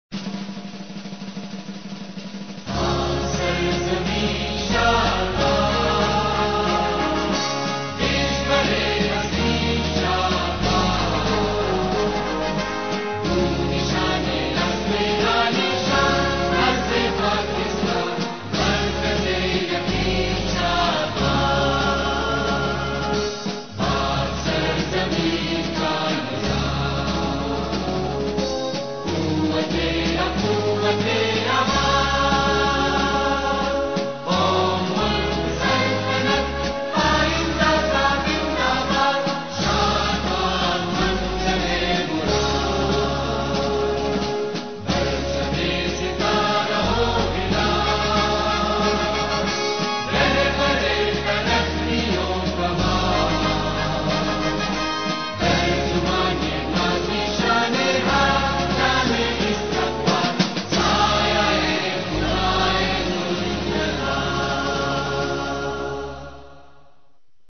Pakistani_anthem.mp3